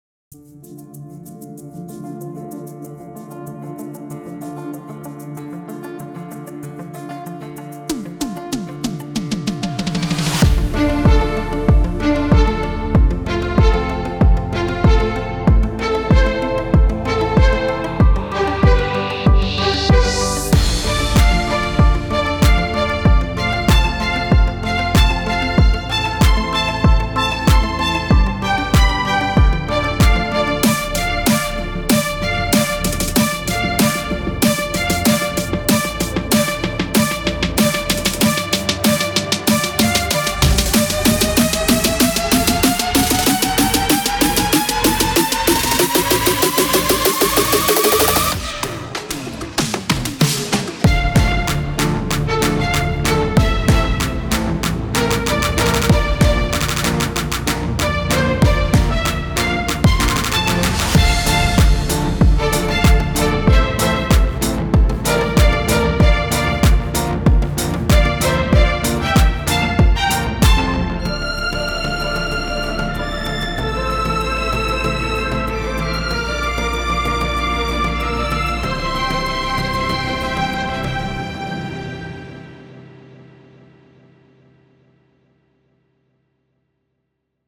טראק דיפ האוס חדש ומטורף שלי - Celestial Tide
מה שיש כאן זה בס קיק של תופים ועוד קצת בס מהפד הוא התכוון לבס ולא לקיק, והפד נמצא גם בשכבות הנמוכות מאוד כדי לתת תחושת סאב